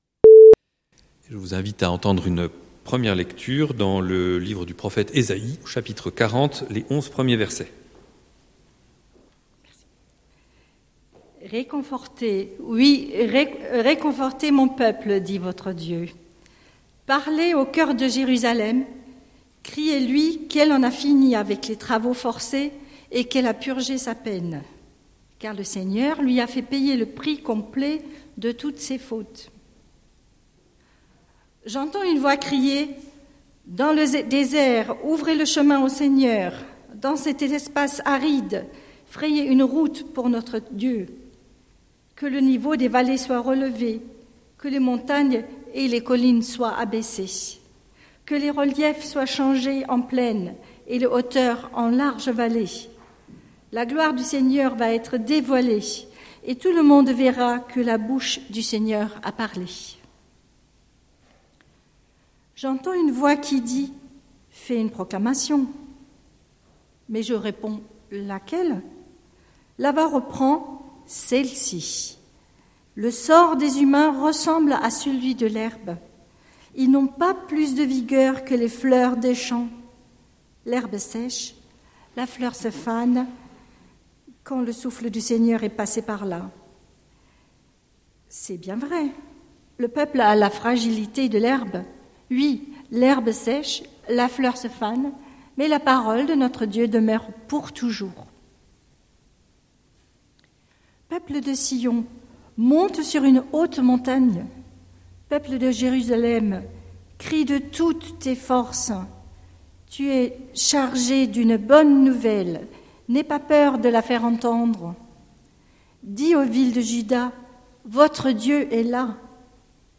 Prédication du 09/01/2022